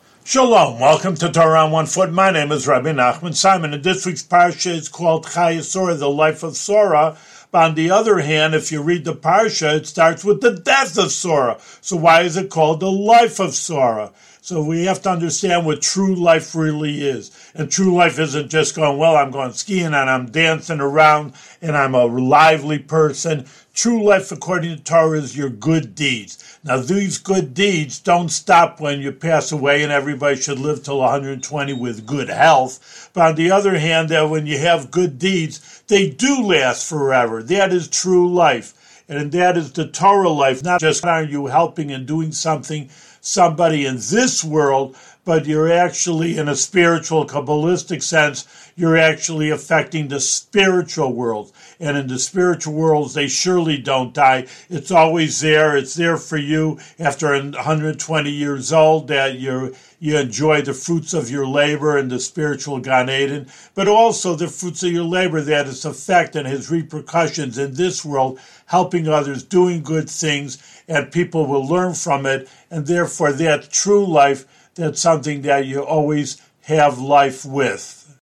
One-minute audio lessons on special points from weekly Torah readings in the Book of Genesis.